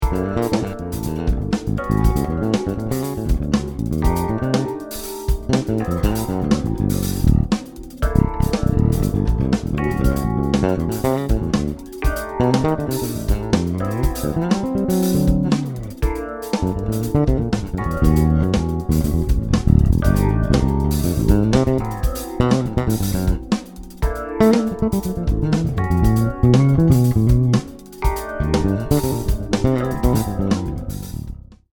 Fclef 5 string Vintage ‘70